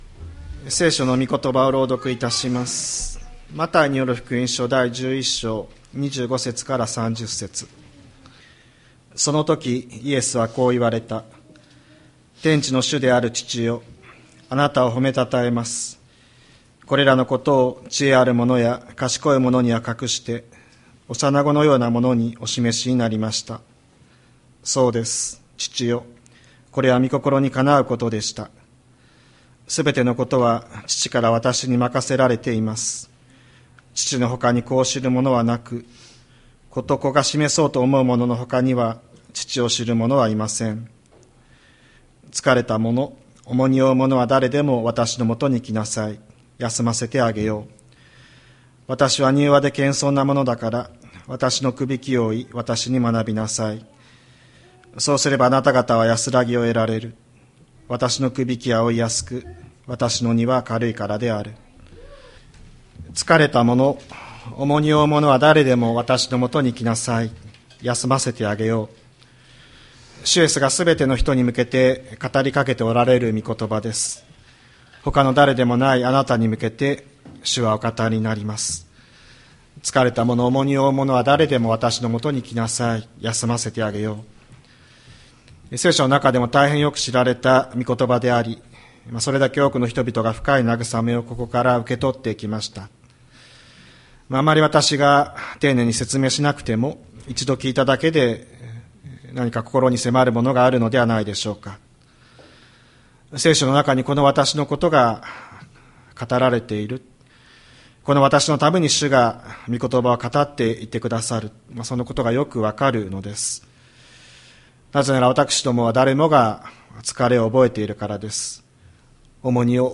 千里山教会 2025年03月09日の礼拝メッセージ。